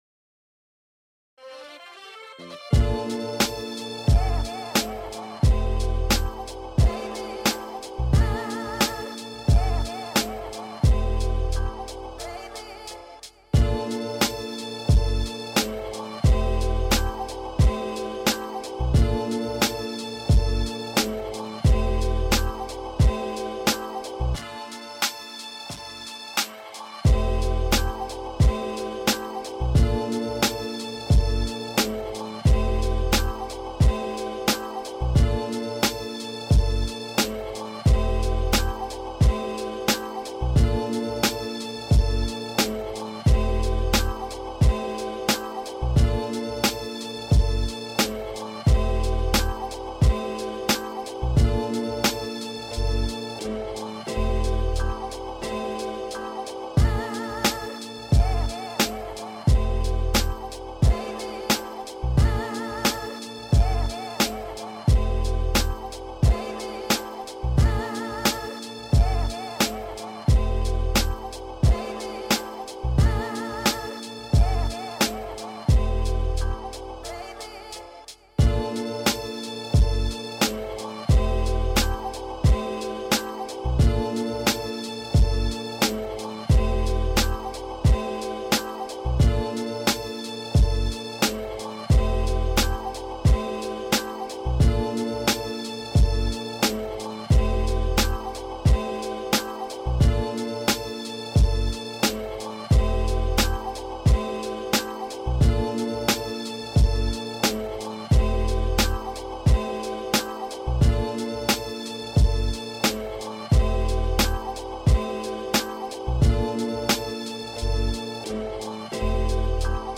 Chopped soulful samples with melodic arrangement